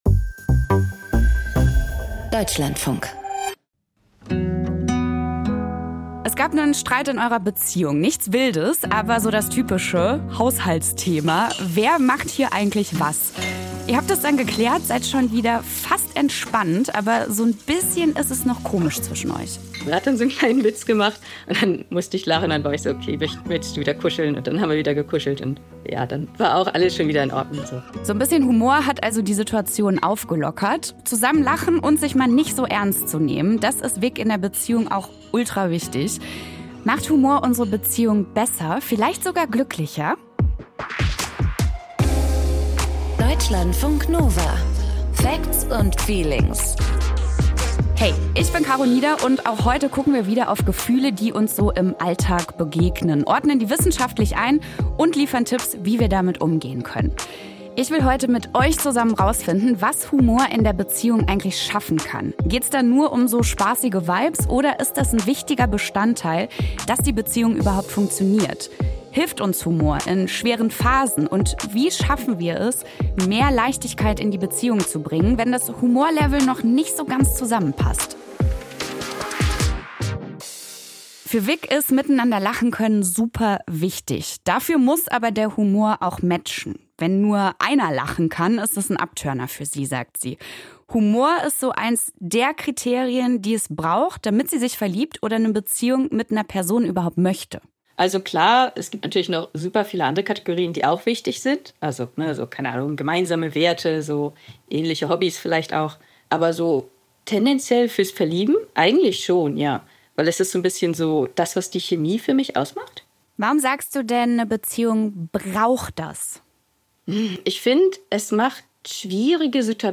Eine Paartherapeutin erklärt, warum Humor ein so starkes Bindungselement ist, wie er Konflikte entschärfen kann und wie weit Humor bei Paaren gehen da...